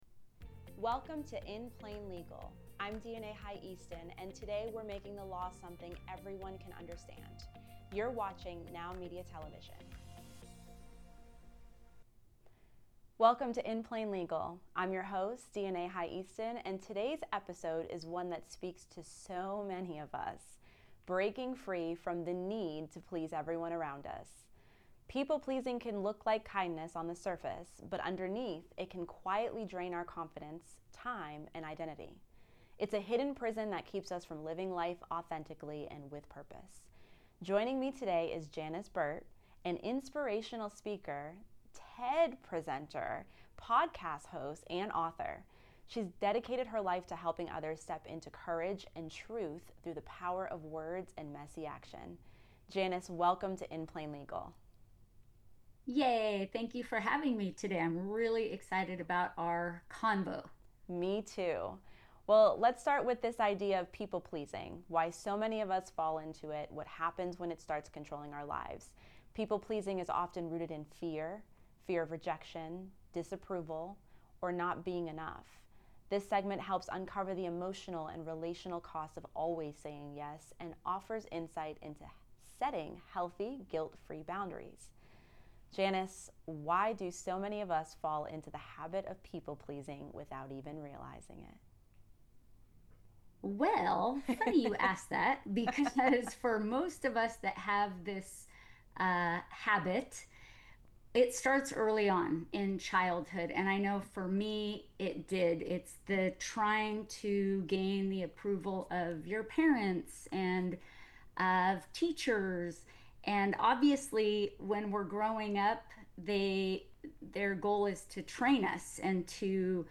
for a deeply human conversation about people pleasing, fear, and the journey back to living authentically.